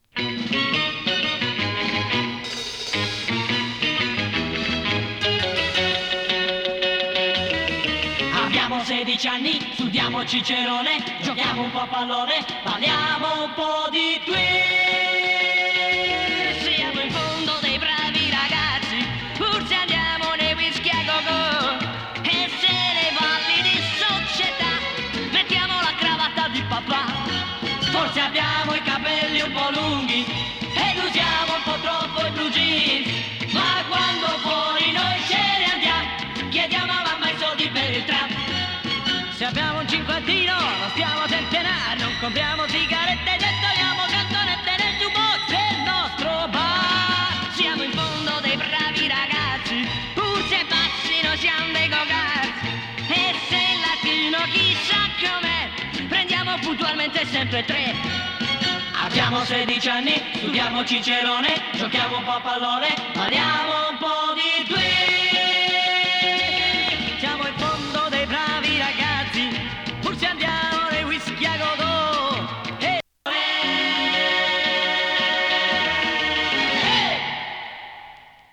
ガールポップ
＊音の薄い部分で軽いチリパチ・ノイズ。
MONO盤です。